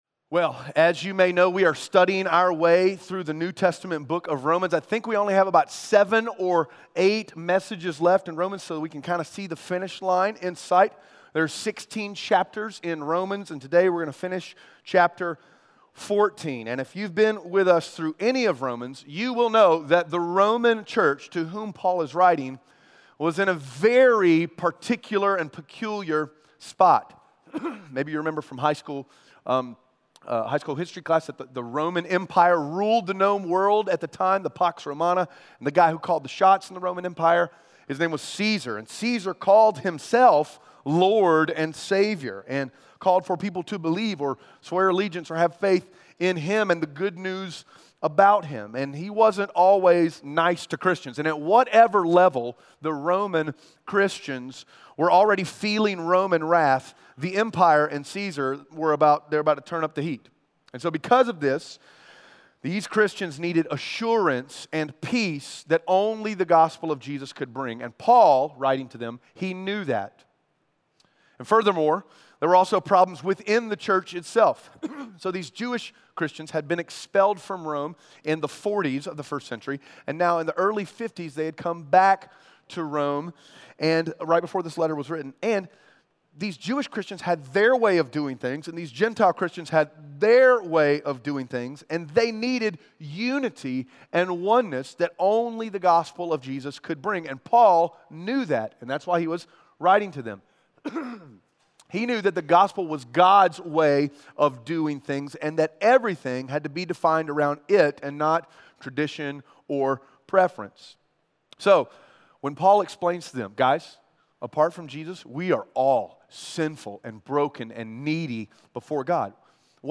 Romans 14:13-23 Audio Sermon Notes (PDF) Ask a Question In the church at Rome, some of the Jewish believers are upset at the Gentile believers for eating non-kosher meat and drinking non-kosher wine.